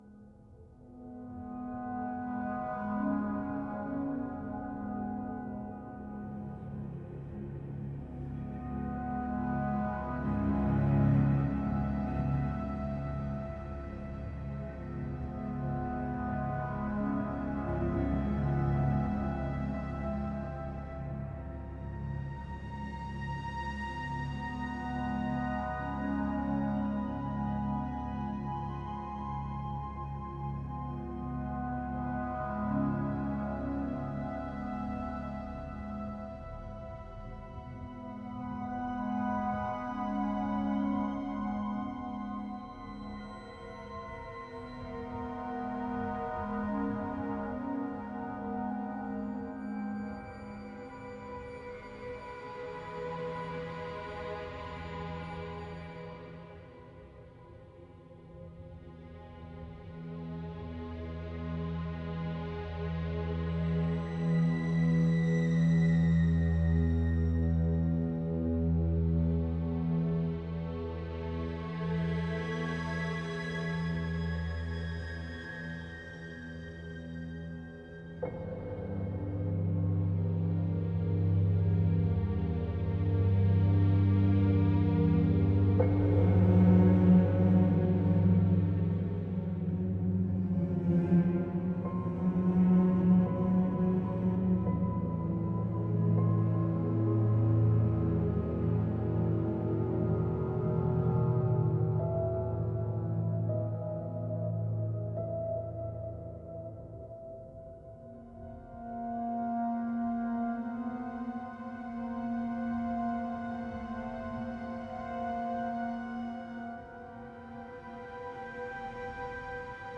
01 - Ambience